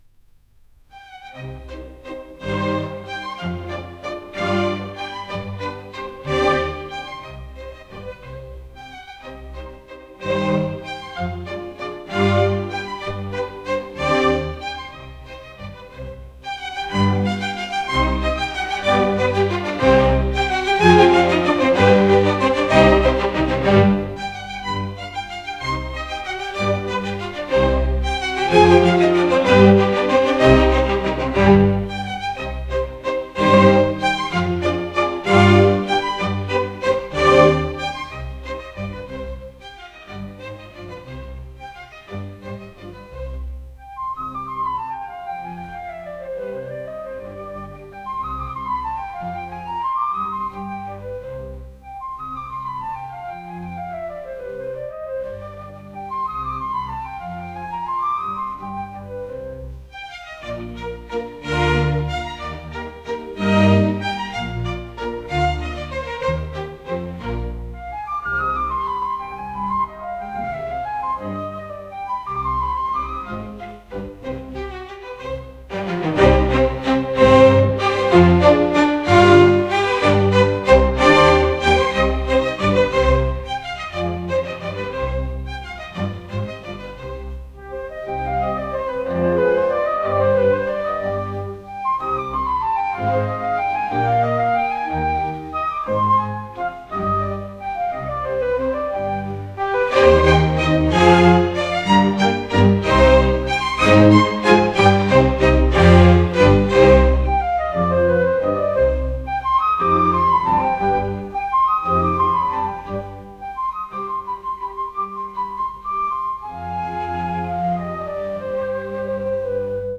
classical | cinematic | romantic